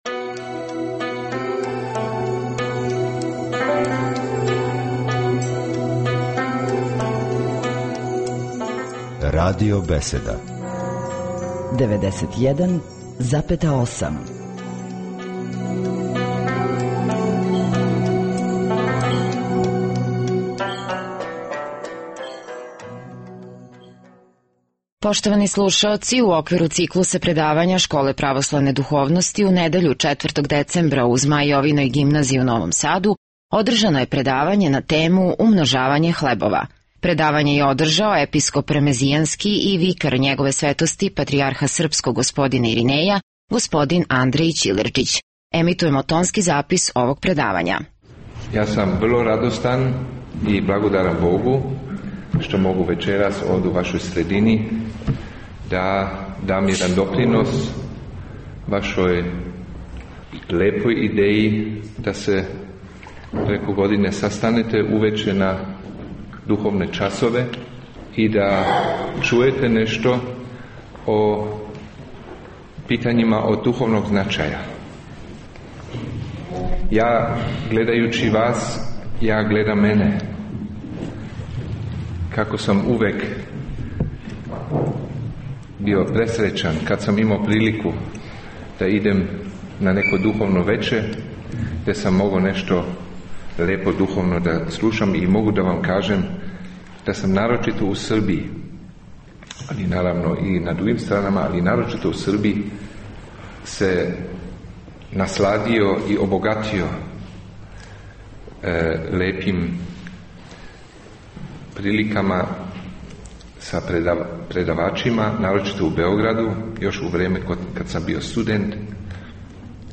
Пред многобројним слушаоцима сабраним у свечаној дворани Змај Јовине Гимназије у Новом Саду, у недељу 4. децембра 2011. године, Његово Преосвештенство Епископ ремезијански г. Андреј одржао је предавање на тему Умножење хлебова.
• Предавање: